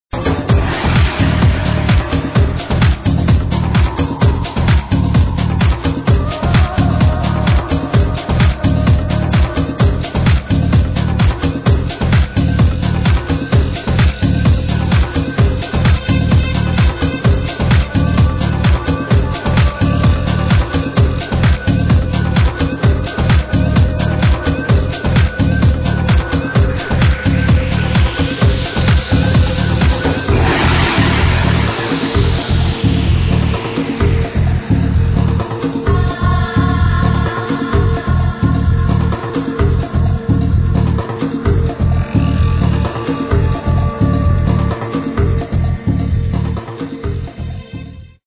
tribal house (not hard but dark)